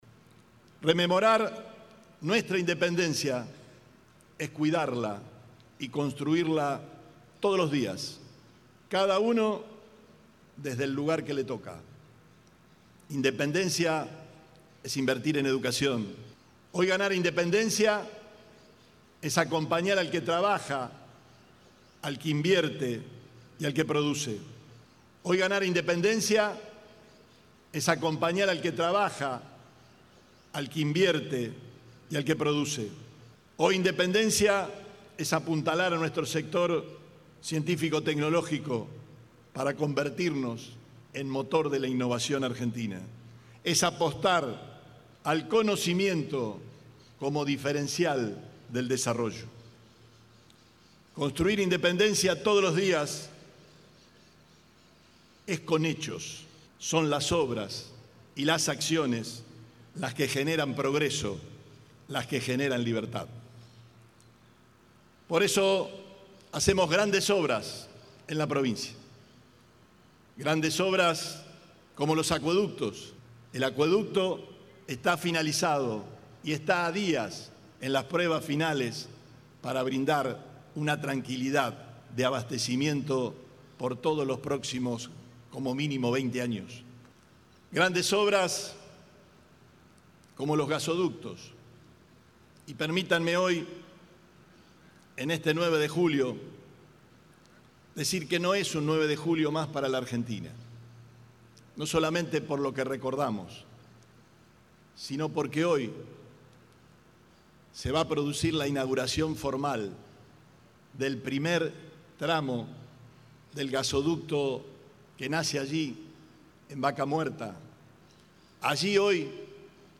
Declaraciones Perotti - segunda parte